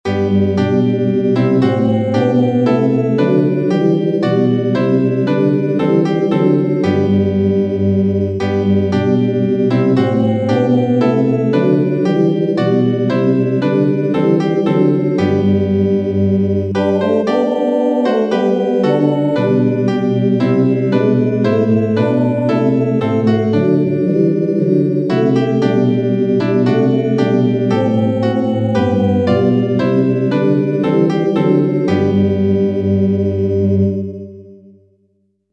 Music – Gosterwood, English melody, arranged by Ralph Vaughan Williams